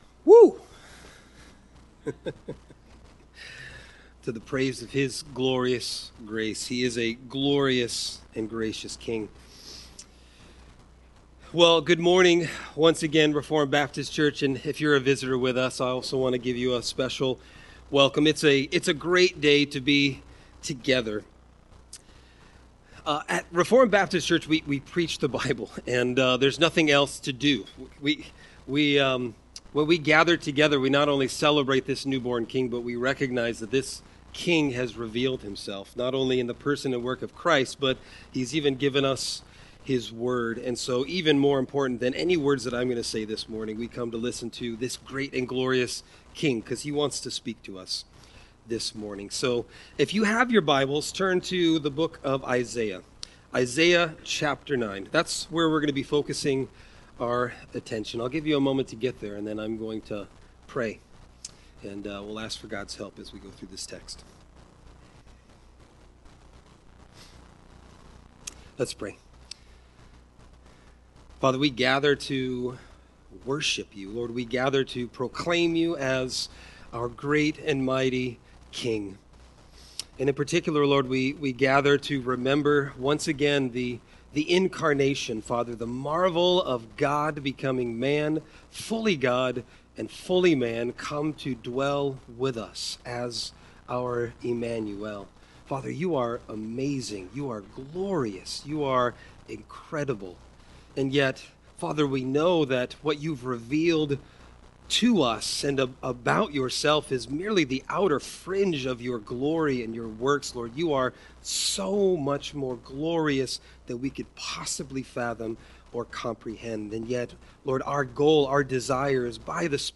Sermon Text: Isaiah 9:1-7